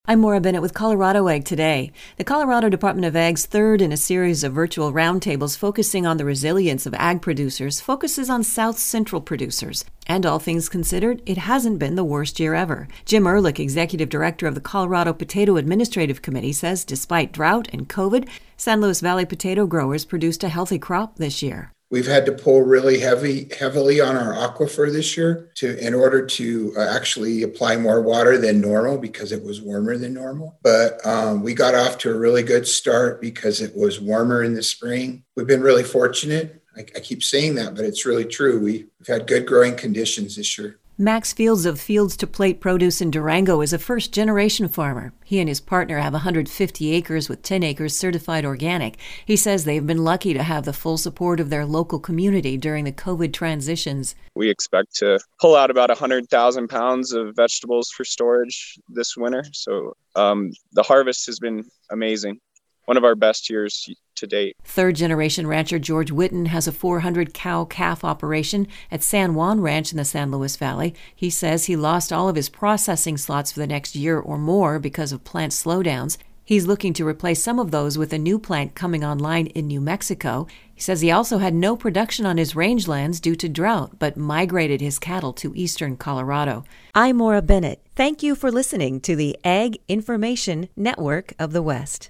It was the third in a series of roundtables featuring ag producers from around the state.